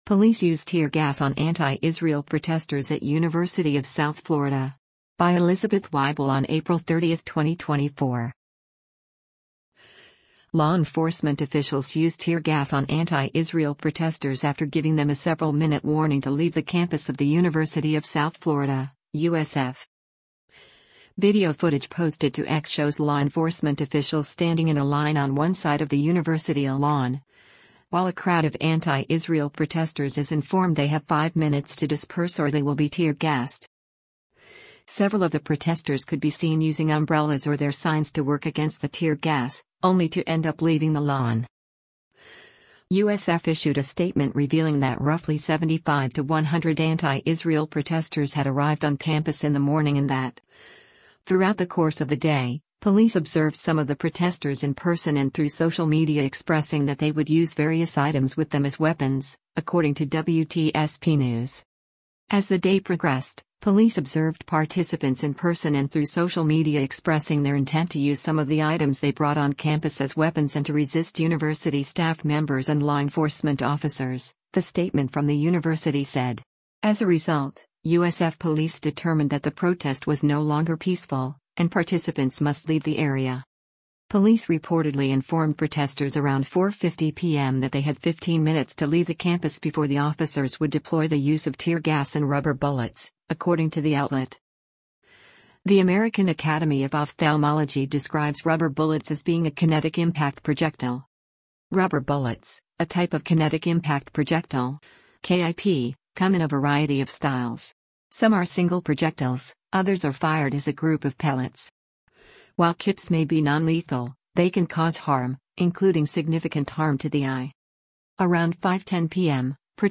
Video footage posted to X shows law enforcement officials standing in a line on one side of the university lawn, while a crowd of anti-Israel protesters is informed they have “five minutes to disperse” or they will be tear-gassed.